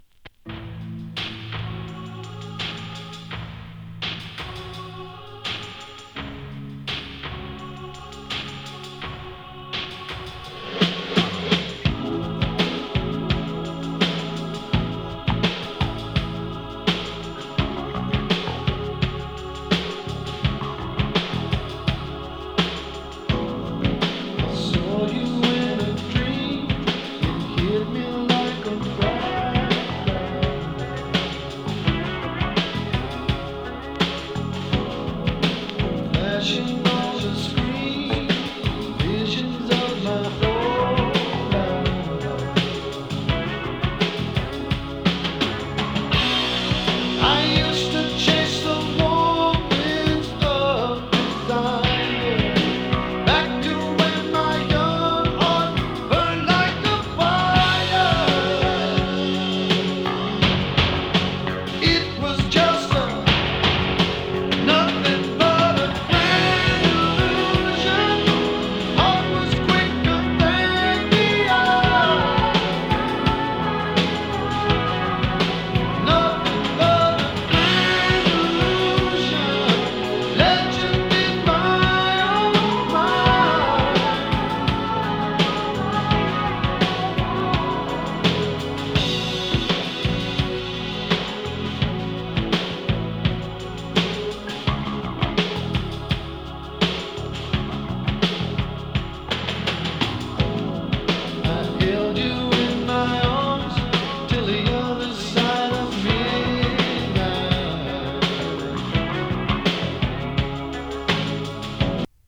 スローハンドの異名を持つ名ギタリスト/シンガー。